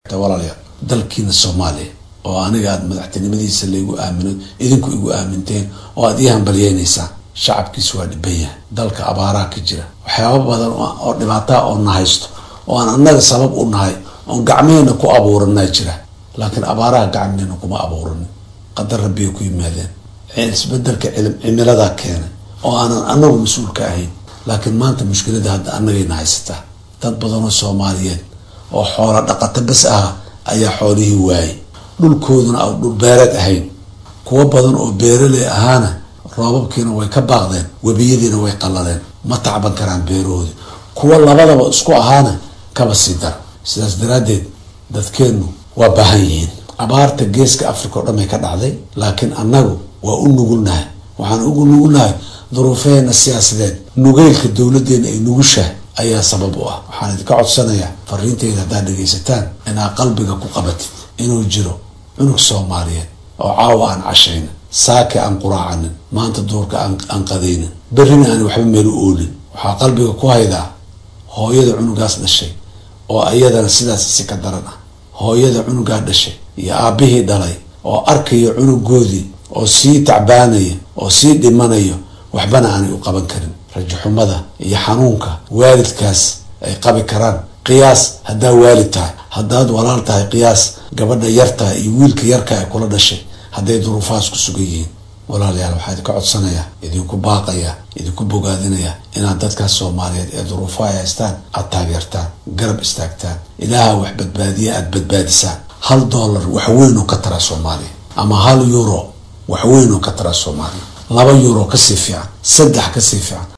Madaxweynaha cusub ee Soomaaliya Xasan Sheekh Maxamuud ayaa jaaliyadda soomaaliyeed ee dalka Jarmalka u diray farriiin uu uga mahadcelinyo hambalyada ee u soo direen ee ku aadan xilka madaxweyne ee dhawaan loo doortay. Waxaa uu sheegay in jaaliyadda Soomaaliyeed ee Jarmalka ay door muhiim ka soo qaateen dib u dhiska dalka iyo hirgelinta dowladnimada Soomaaliya. Waxaa uu ugu baaqay inay ka qayb qaataan samatabixinta dadka ay abaartu aadka u saameysay ee dalka Soomaaliya.